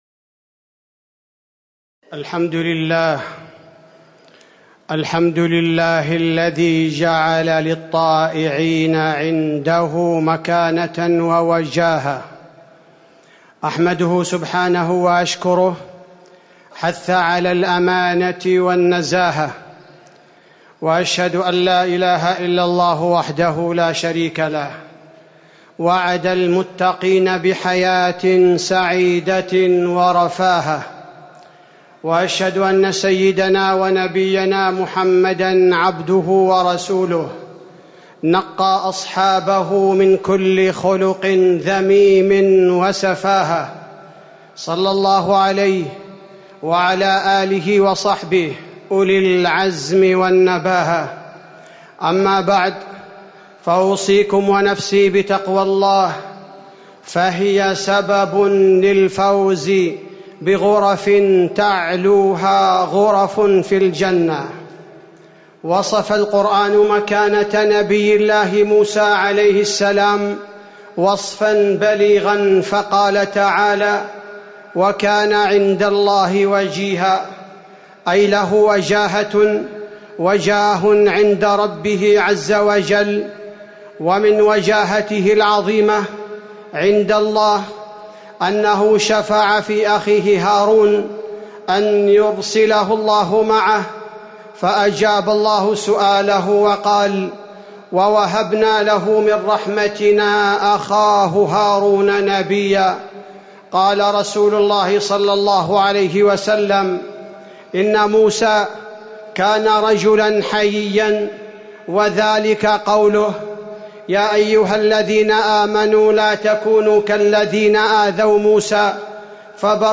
تاريخ النشر ٢٩ رجب ١٤٤٠ هـ المكان: المسجد النبوي الشيخ: فضيلة الشيخ عبدالباري الثبيتي فضيلة الشيخ عبدالباري الثبيتي الوجاهة عند الله نقاء وارتقاء